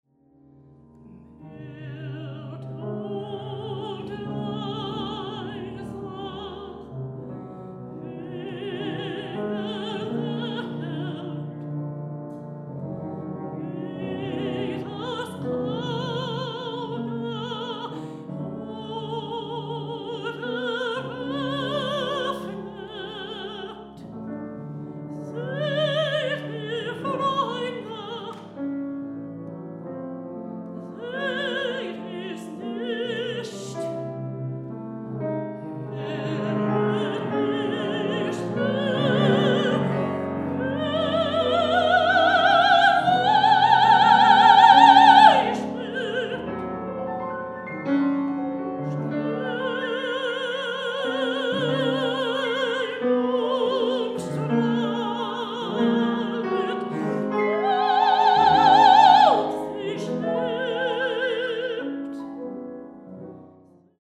a personal profile of my career as a professional opera singer as well as vocal
pianist